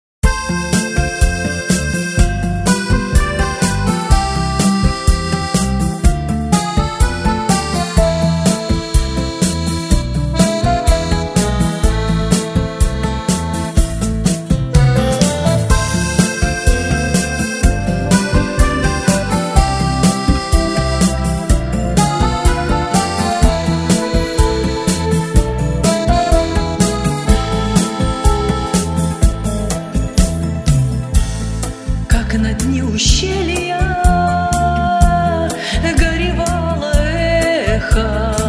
Песни были записаны, аранжированы и смонтированы в домашней студии.
• Аранжировка: гитара, кейбоард
• Жанр: Авторская песня